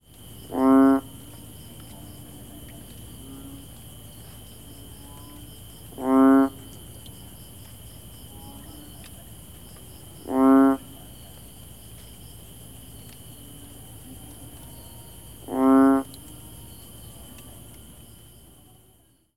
Toads | l'audio journal
Puerto Princessa, Philippines Jan 2011